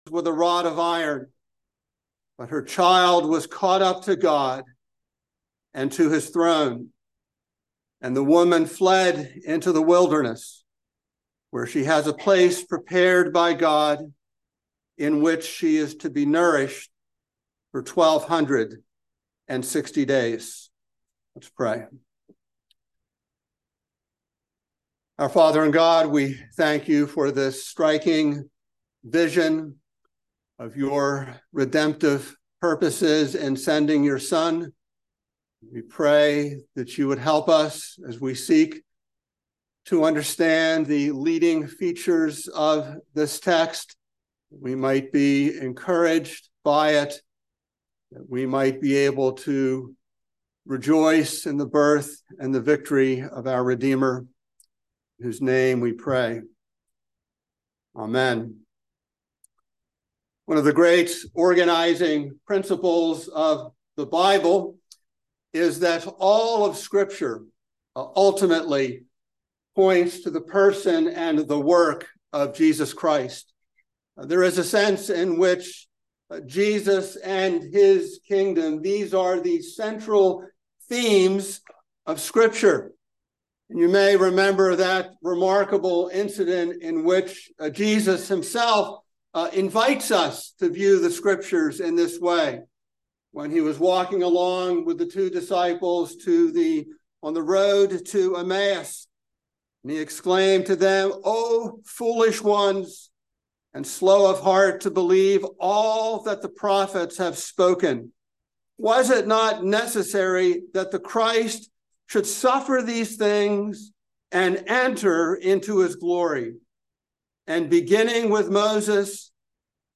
by Trinity Presbyterian Church | Jan 10, 2024 | Sermon